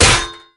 Armor